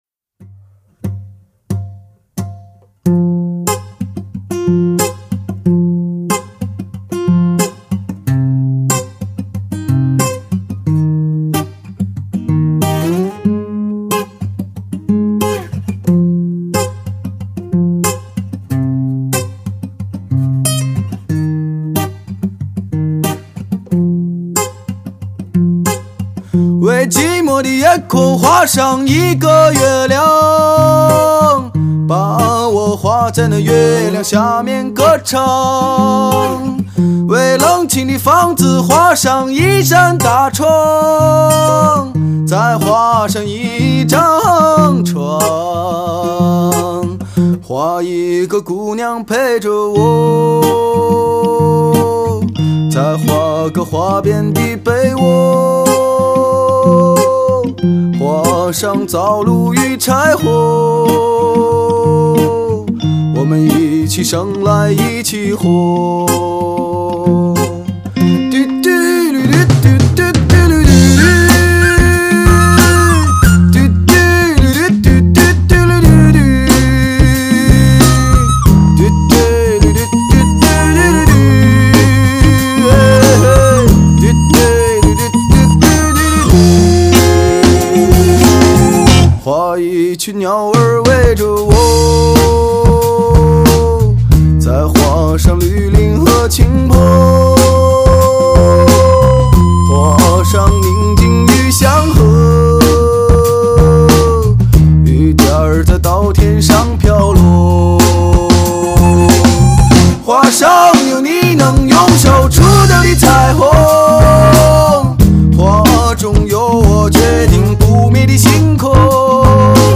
城市民谣
音质、解像度、音量感、歪感、透明感五大方面均优于普通CD，低频比XRCD24的表现要好，更加有现场感！